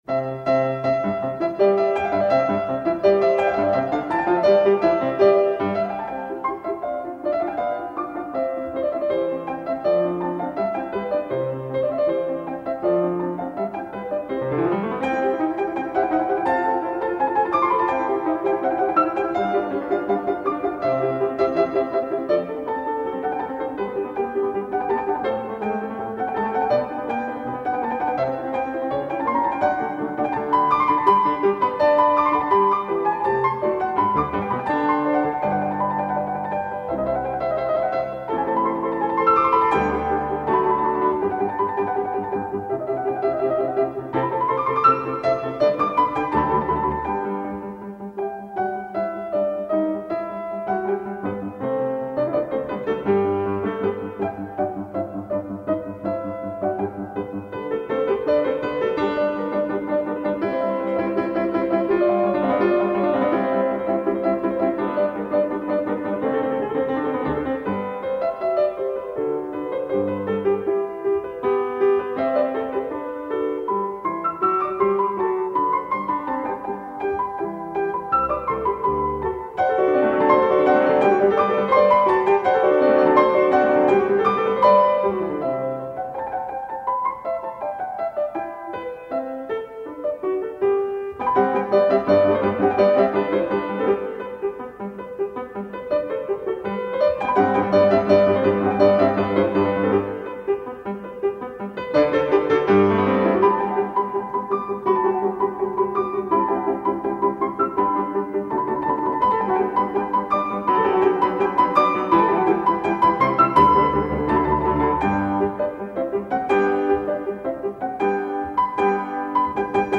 Дивные мелодии, близкие народным ирландским песням; сигналы почтового рожка (английские почтальоны из почтовой кареты, запряжённой лошадьми, трубили громко в рожок. И все выбегали встретить почту), интонации пения кукушки – все очень весело, с юмором.
06-Sonata-No.-4-for-Piano-Four-Hands-in-C-Major-II.-Allegro.mp3